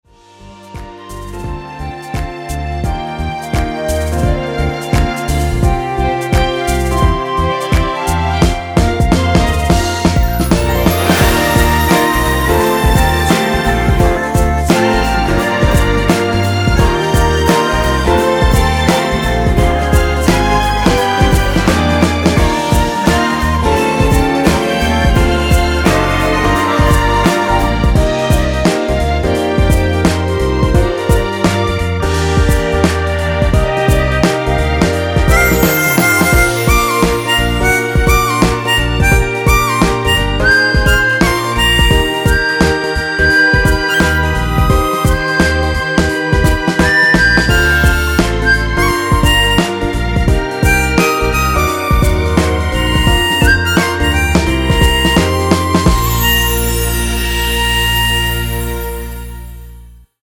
엔딩이 페이드 아웃이라서 노래하기 편하게 엔딩을 만들어 놓았으니 미리듣기 확인하여주세요!
원키에서(+2)올린 멜로디와 코러스 포함된 MR입니다.
Bb
앞부분30초, 뒷부분30초씩 편집해서 올려 드리고 있습니다.